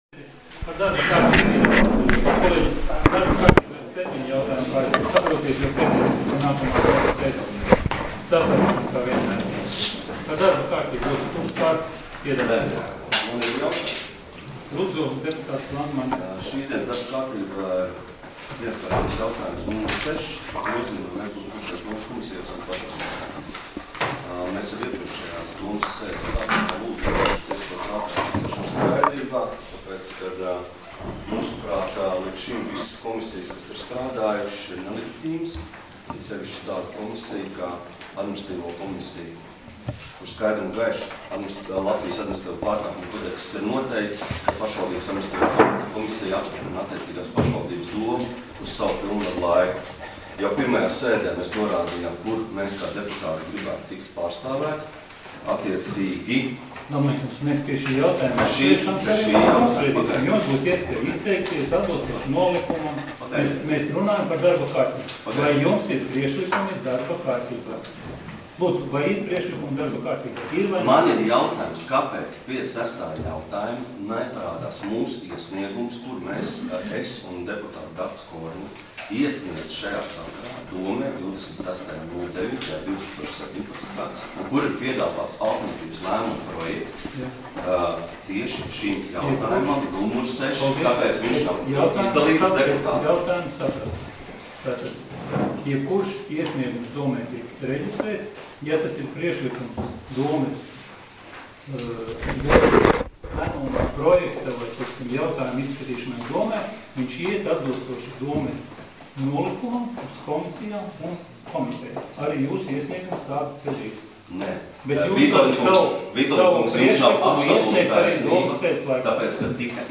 Domes sēdes 29.09.2017. audioieraksts